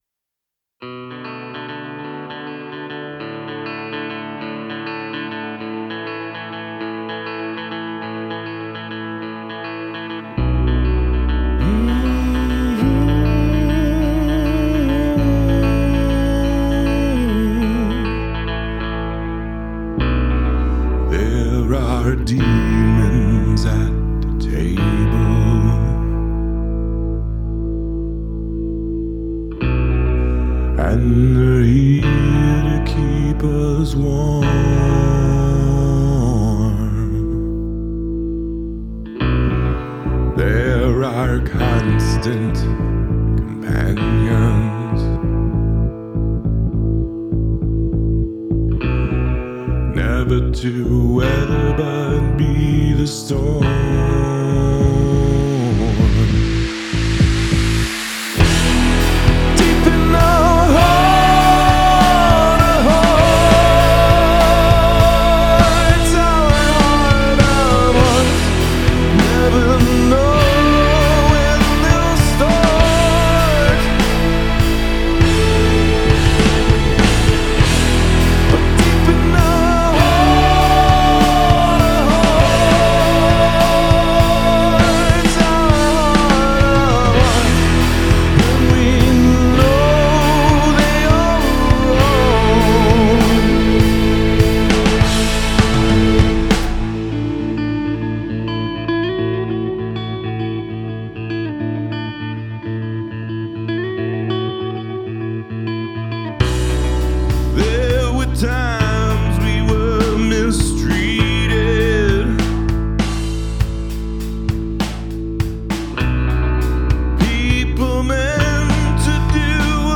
Heart of Hearts mix help needed (rock song)